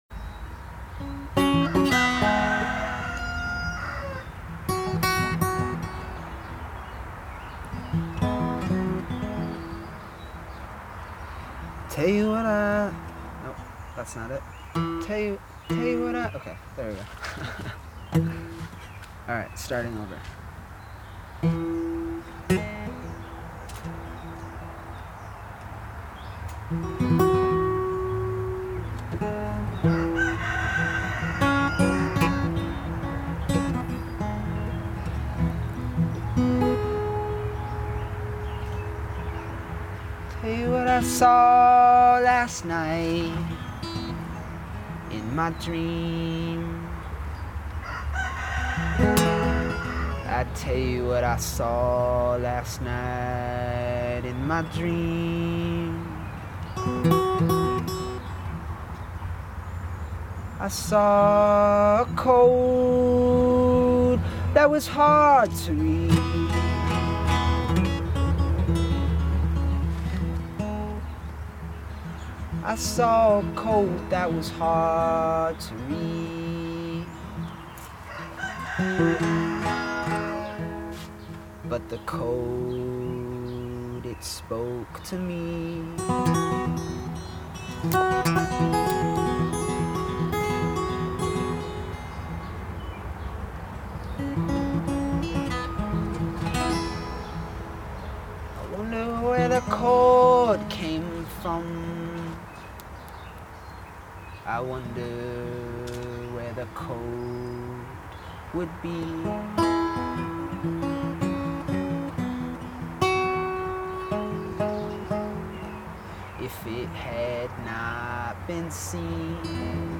His music too is noticeably more concentrated and restrained